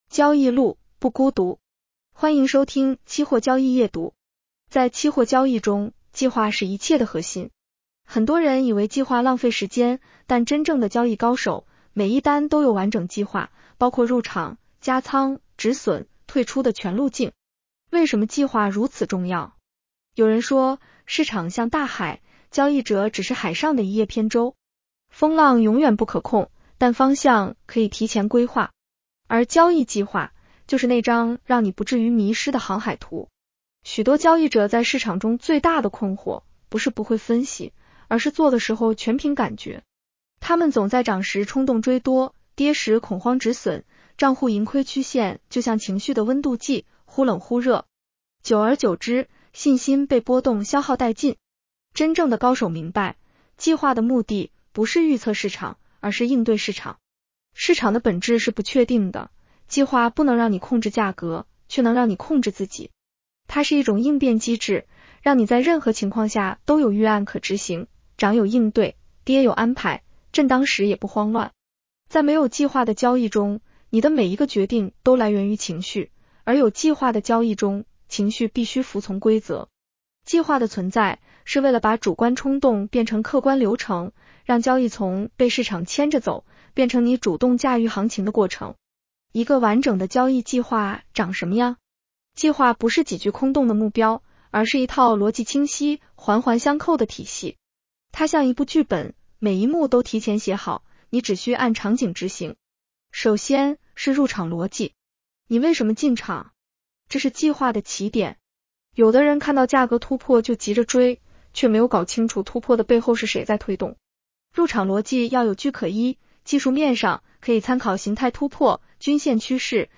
女声普通话版 下载mp3